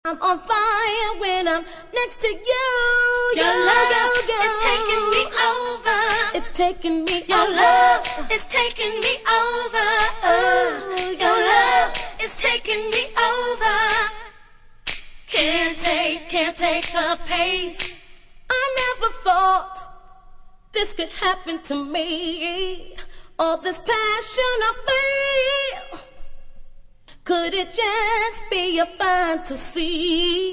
[90SHOUSE]